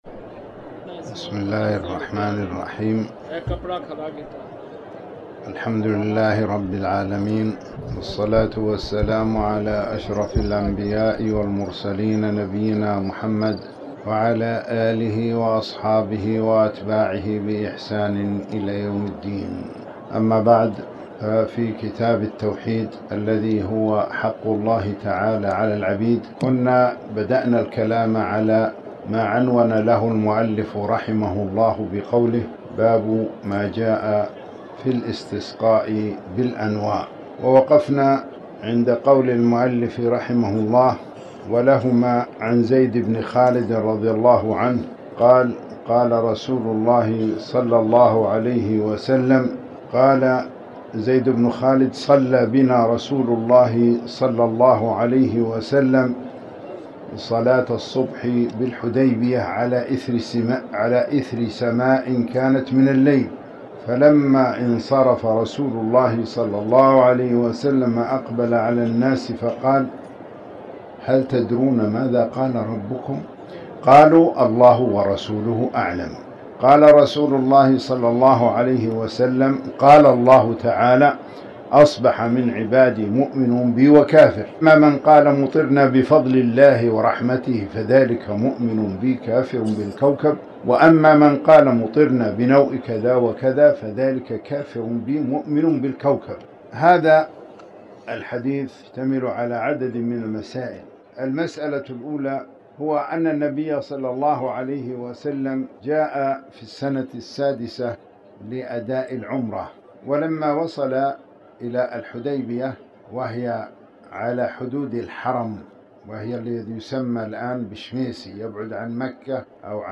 تاريخ النشر ١٢ جمادى الآخرة ١٤٤٠ هـ المكان: المسجد الحرام الشيخ